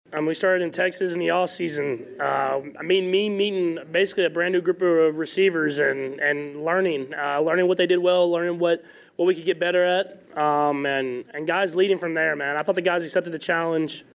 Chiefs quarterback Patrick Mahomes talks about how his receivers stepped up.